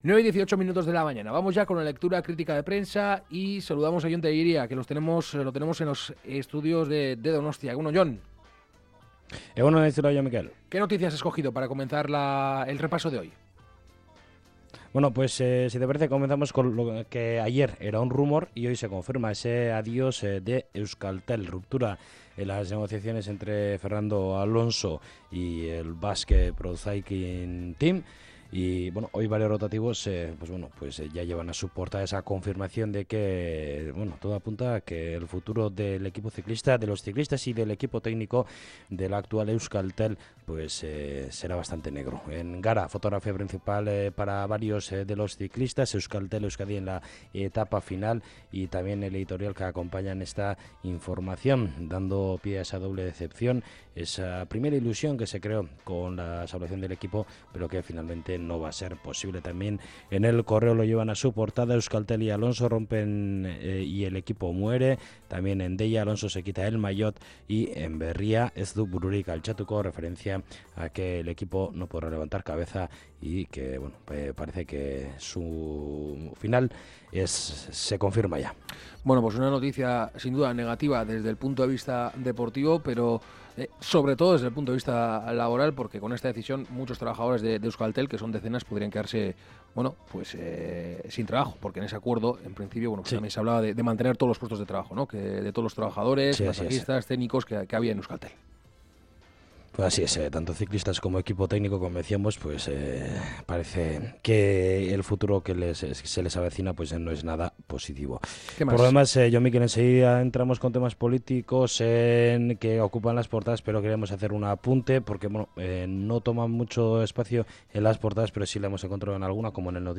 Puedes seguir la lectura de prensa mientras observas las portadas del día.